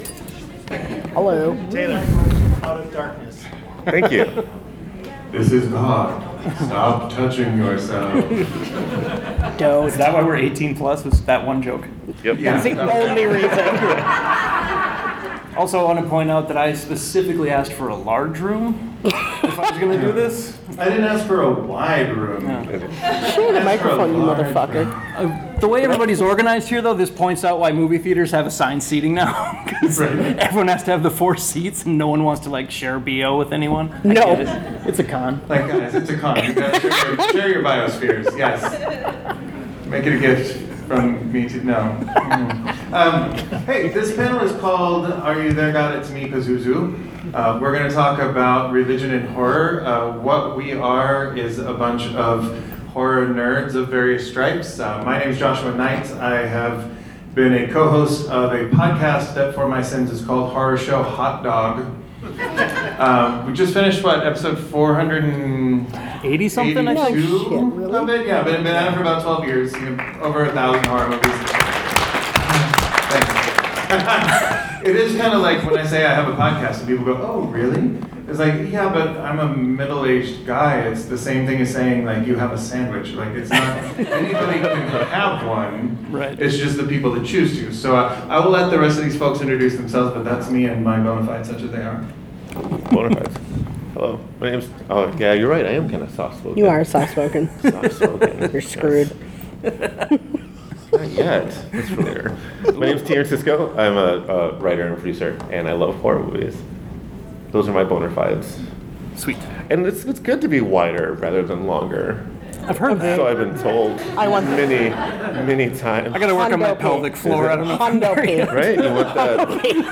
Our audience also asked us some great questions and were a delight to meet and chat with as well.
HSHD-Twin-Cities-Con-2024-Friday-Panel.mp3